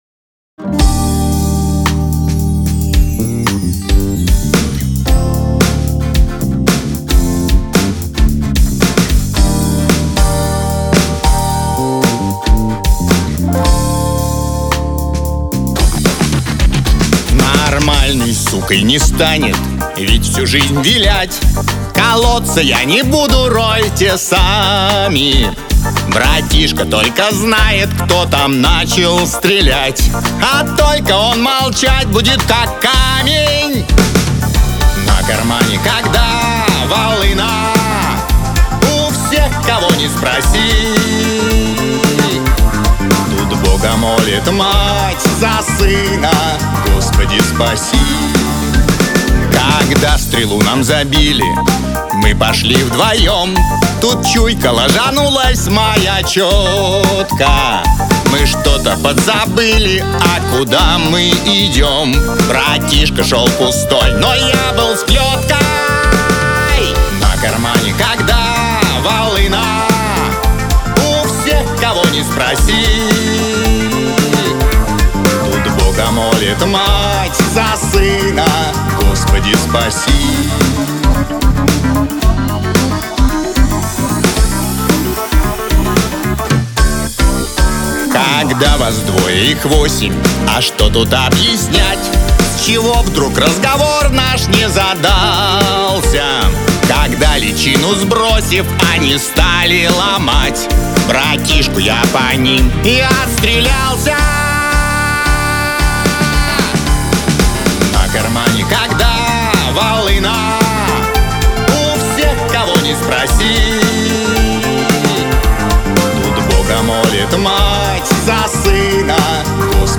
я в лоб подсоединил внешний преам к преампу карты по хлр, да и прописал материал.
сетап нойман н87, преамп фокусрайт ред 7, карта стейнберг 824.